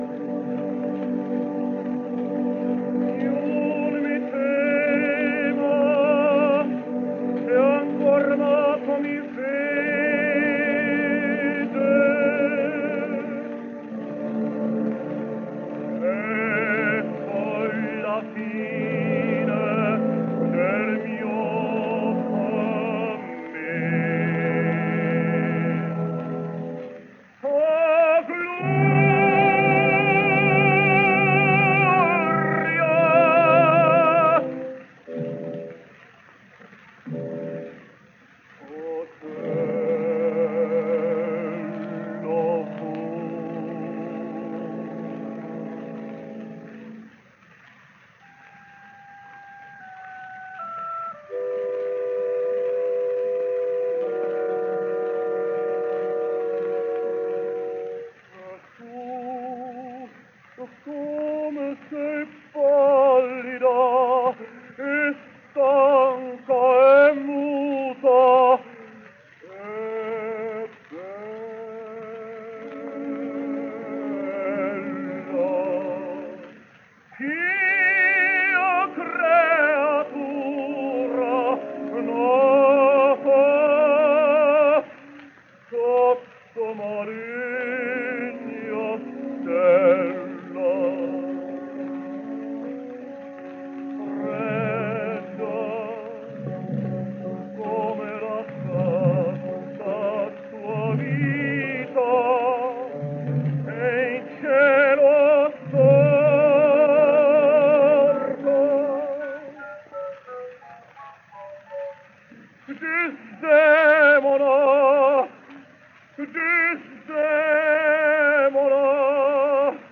Here are some scarce Odeon records then, from this, his Metropolitan Opera debut made in 1912, during his period in America and sung in Italian, you’ll notice.